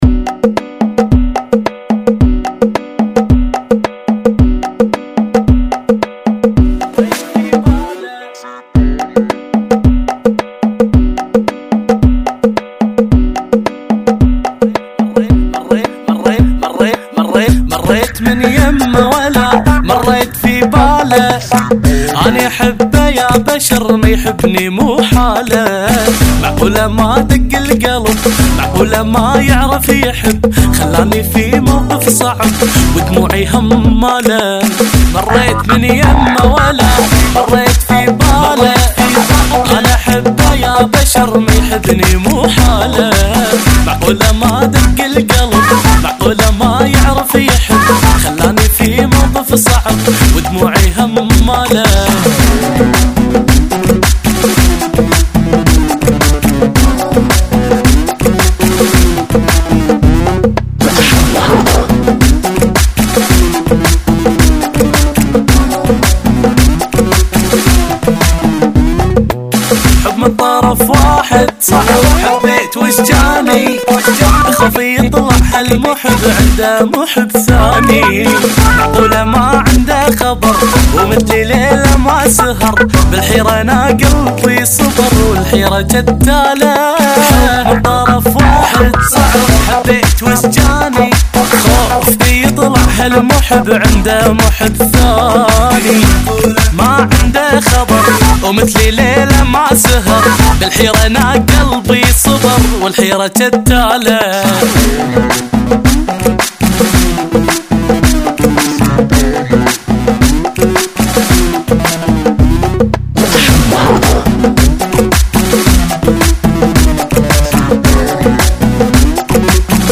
Funky [ 110 Bpm